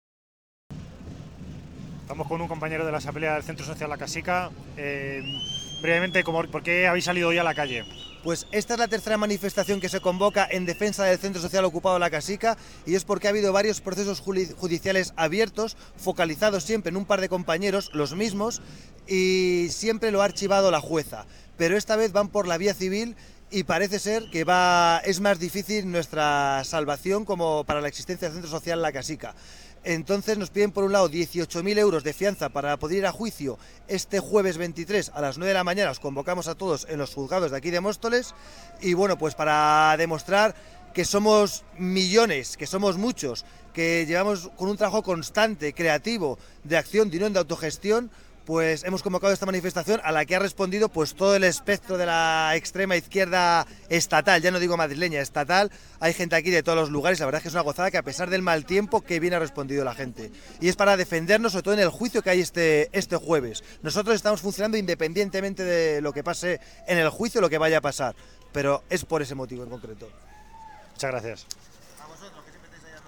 20.30: [Audio] La Haine entrevista a miembro de la asamblea del Centro Social Okupado La Casika, que nos da su valoración de la manifestación de hoy.